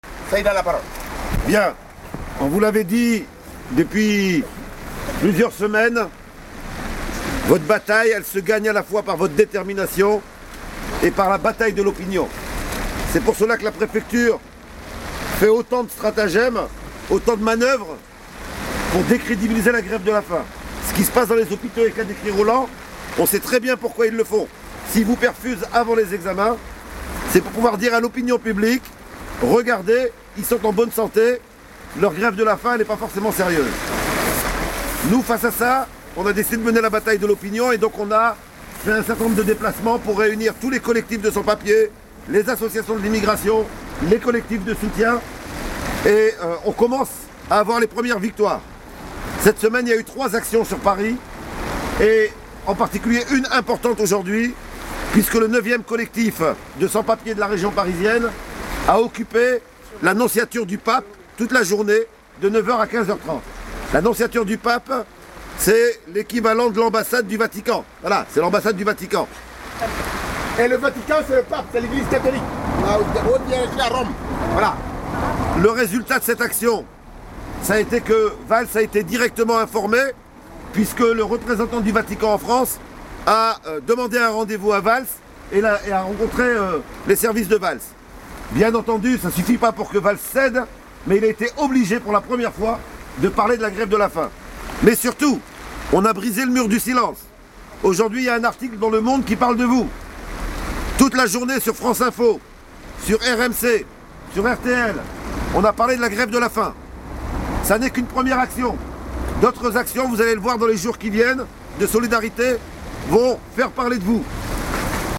3 enregistrements audio effectués dans la tente des sans papiers du CSP59 en grève de la faim depuis 60 jours, ce Lundi 31 décembre 2012 vers 21h.
Le bruit de fond, c’est la pluie sur la toile de la tente.